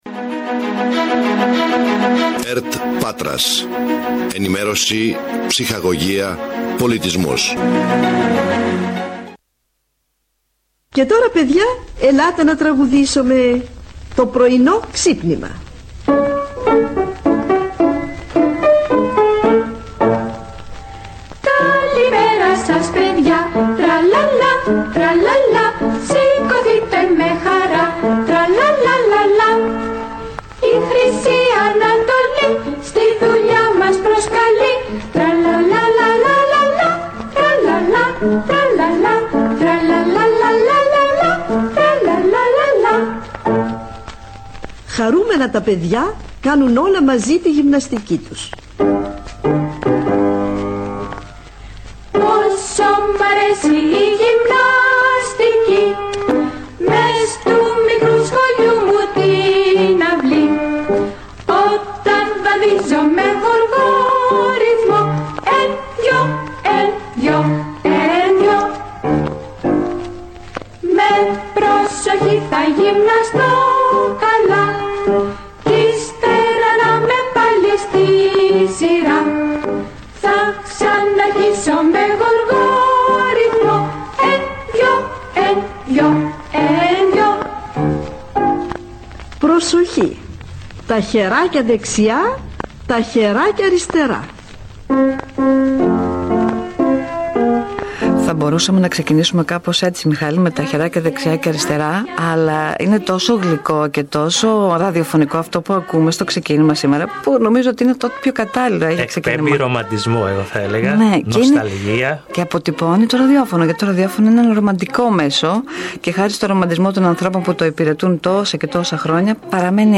Ειδικότερα, οι δημοσιογράφοι της ΕΡΤ Πάτρας θα συνομιλήσουν:
Επίσης, θα ακουστούν ηχητικά αποσπάσματα που έχουν αφήσει έντονο αποτύπωμα στην ιστορία της ΕΡΤ και προκαλούν συγκίνηση μέχρι σήμερα στους παλιότερους ραδιοφωνικούς ακροατές της.